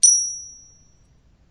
指形镲片 " 指形镲片 side03
描述：这个包包含了指钹的声音样本。包括了一起撞击时的击打和窒息，以及从边缘一起撞击时的声音。还有一些效果。
Tag: 手指钹 管弦乐 打击乐